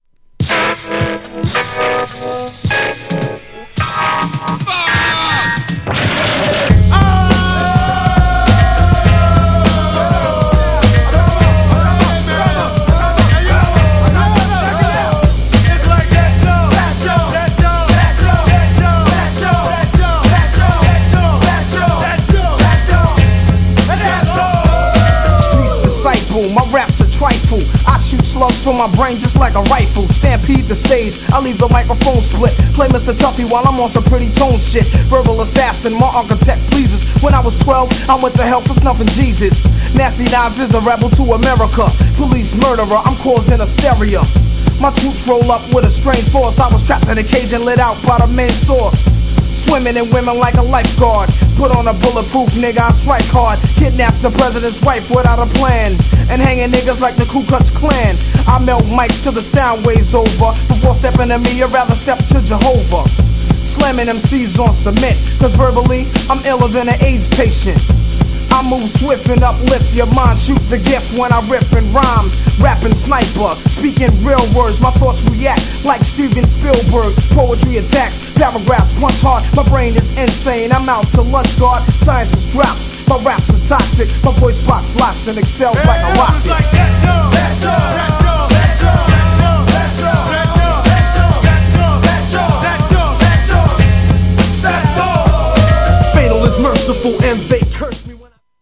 GENRE Hip Hop
BPM 101〜105BPM
# DOPEなビート # GROOVYなHIPHOP